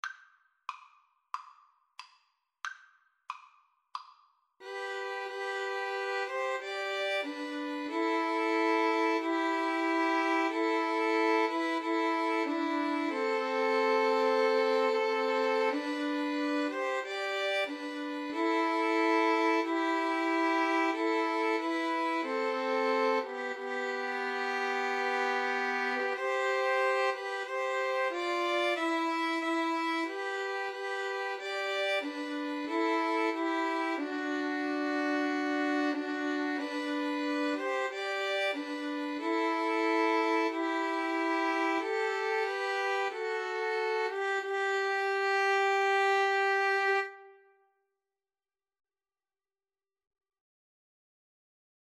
Andante = c. 92
2-Violins-Cello  (View more Easy 2-Violins-Cello Music)
Classical (View more Classical 2-Violins-Cello Music)